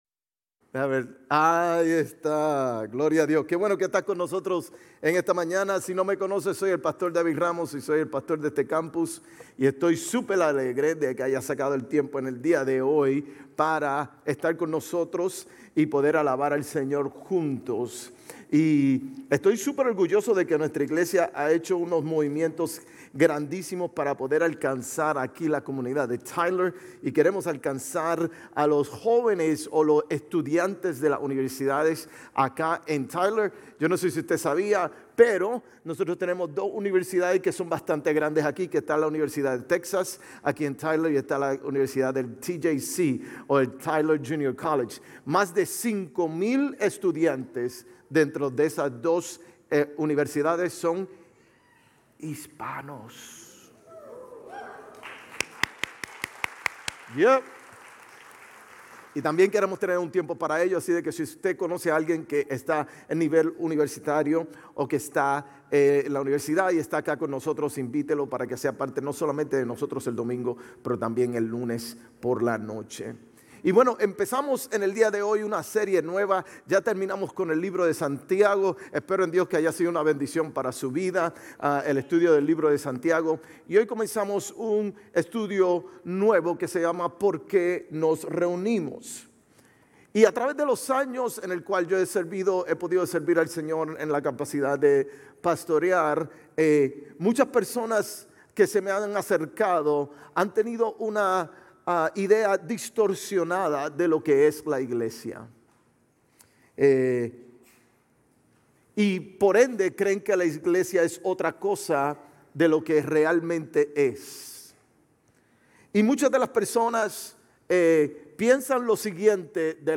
GCC-GE-August-27-Sermon.mp3